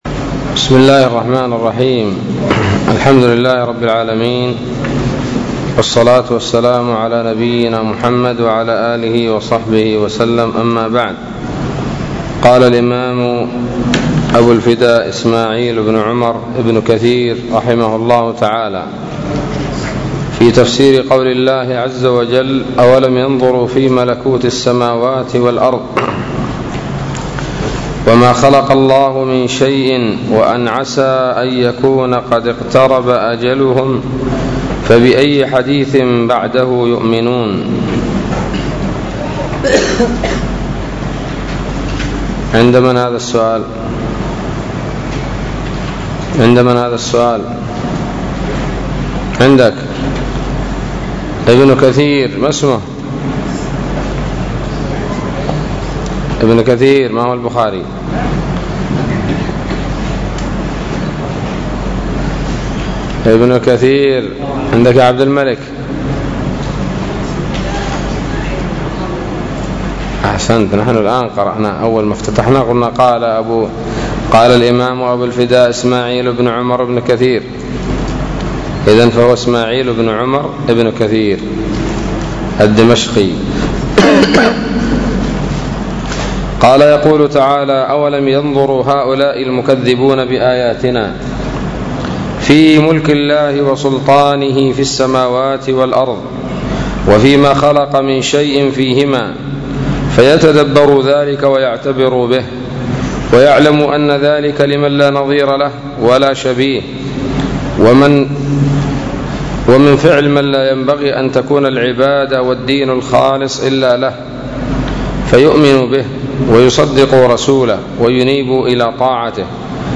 الدرس السبعون من سورة الأعراف من تفسير ابن كثير رحمه الله تعالى